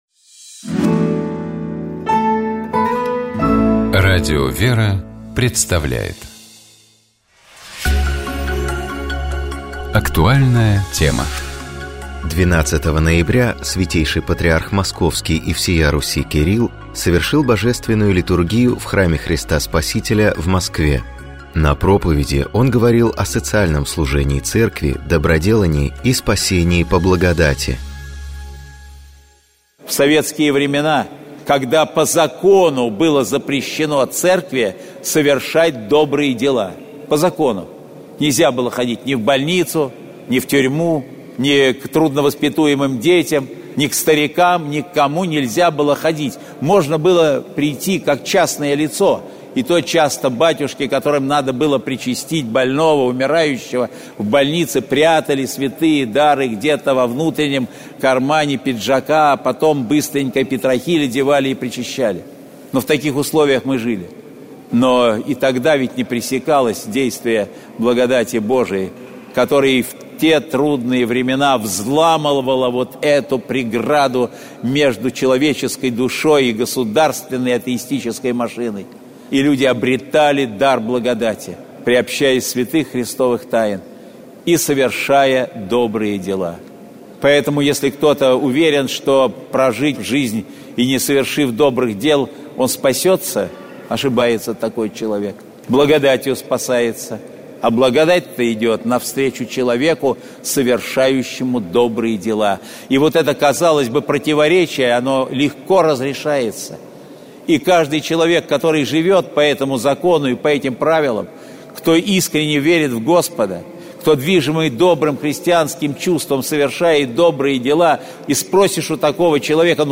Святейший Патриарх Московский и всея Руси Кирилл совершил Божественную литургию в Храме Христа Спасителя в Москве.